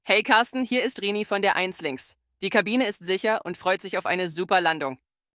CallCabinSecureLanding.ogg